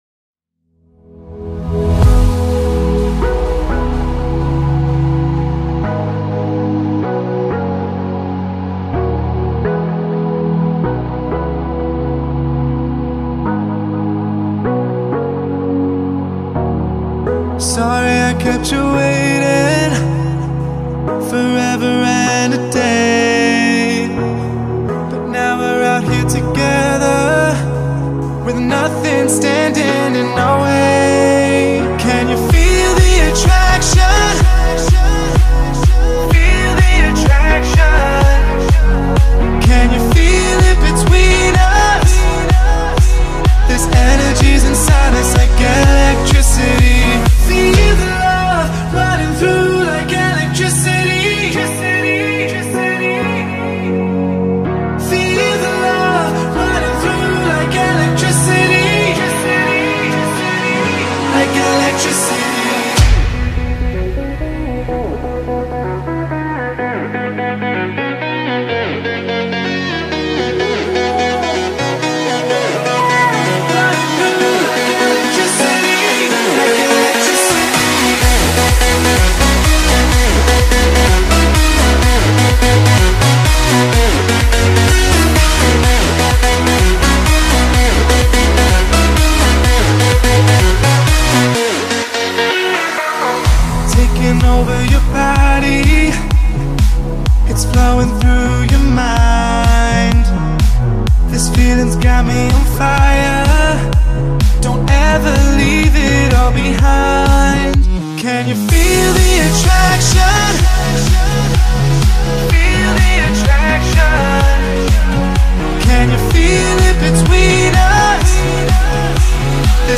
House, Hopeful, Euphoric, Energetic, Happy, Epic